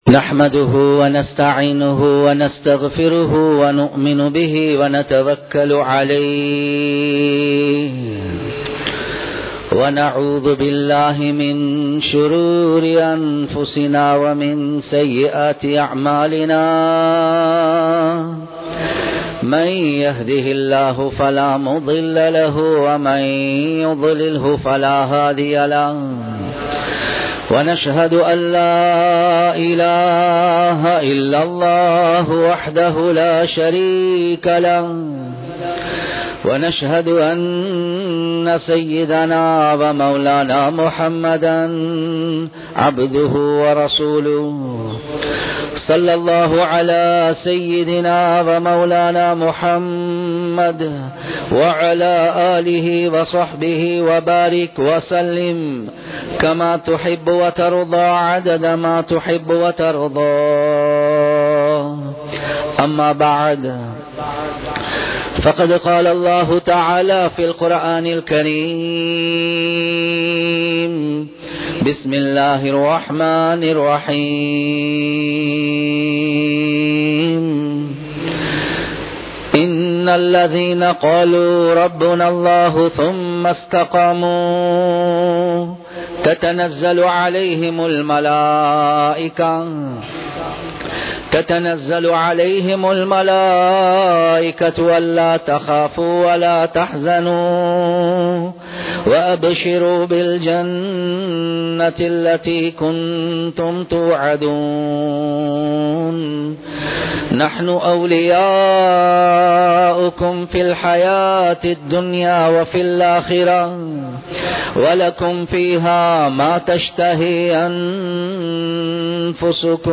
கப்ரை நோக்கி நகரும் மனிதன் | Audio Bayans | All Ceylon Muslim Youth Community | Addalaichenai
Muhiyadeen Jumua Masjith